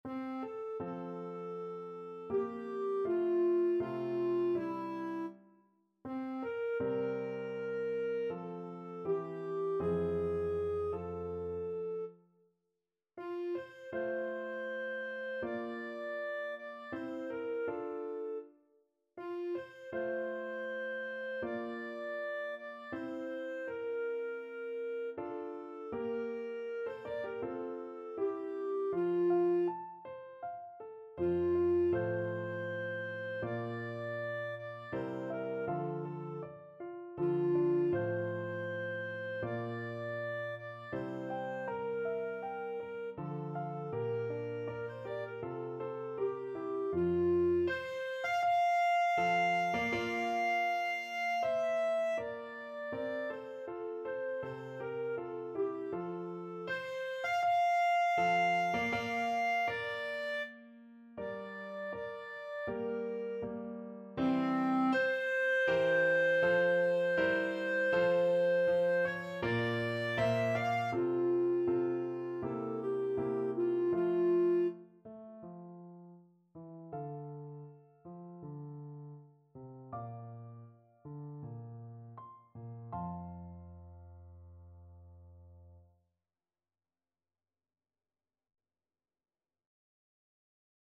Clarinet version
Andante
4/4 (View more 4/4 Music)
C5-F6
Classical (View more Classical Clarinet Music)